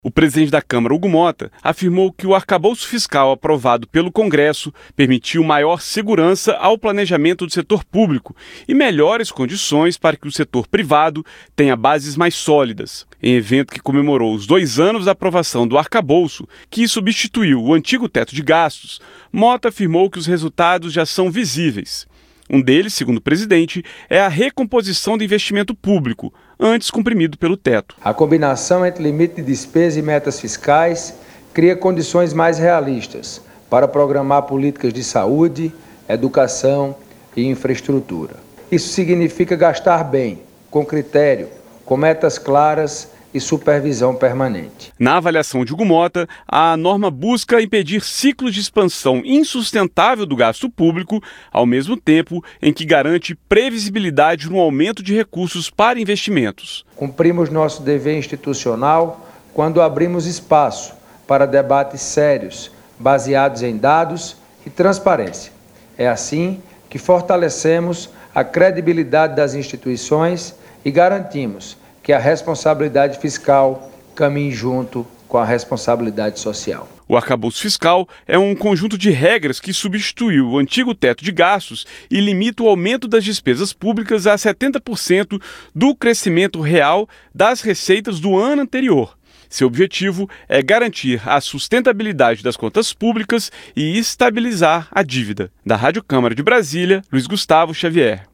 Motta discursa em evento